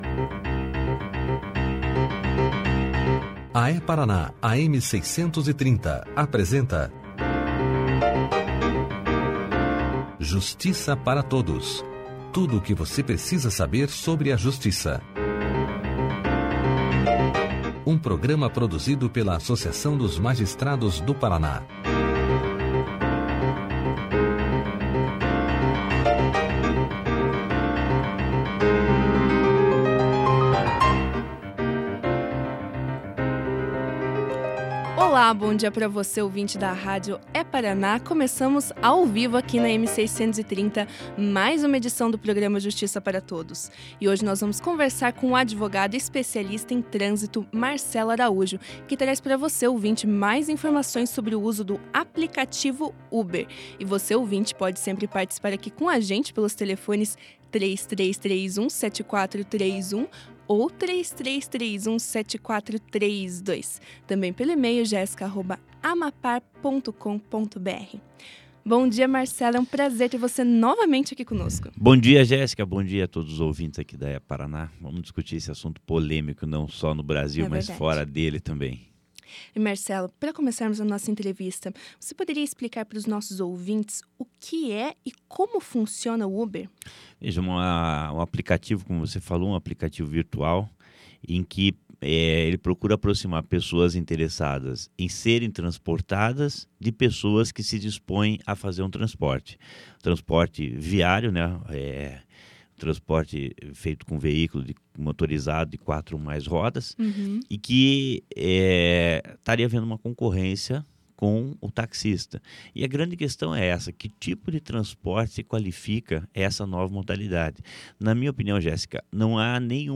O que você tinha ao realizar o telefonema ou faz ainda, o telefonema para um ponto de táxi, não há diferença ao que faz hoje, que no caso é utilizar do aplicativo para chamar um meio de mobilidade”, declarou o advogado durante a entrevista.